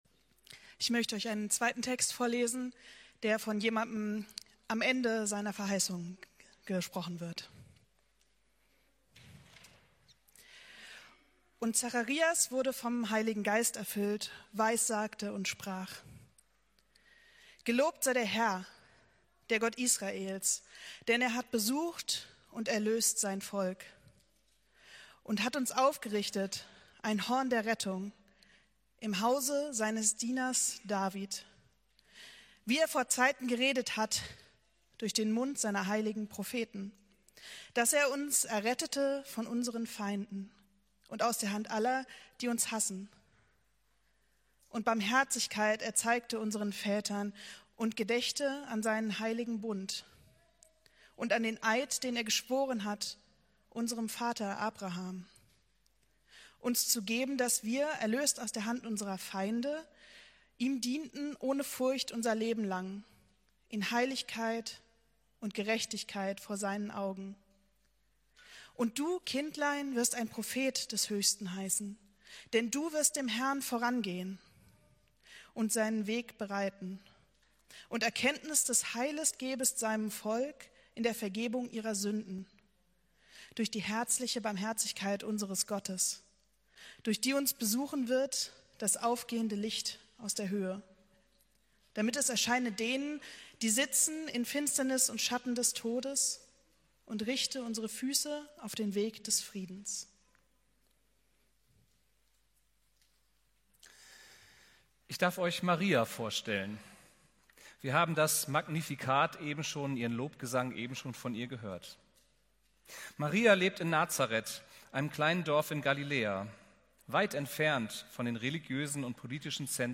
Predigt vom 28.12.2025